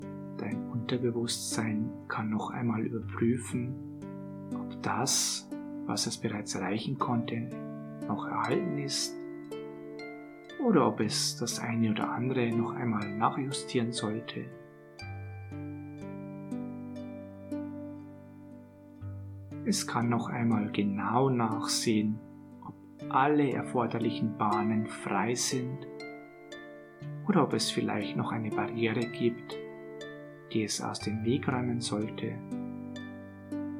G2005-Folgesitzung-Hypnotisches-Magenband-maennliche-Stimme-Hoerprobe.mp3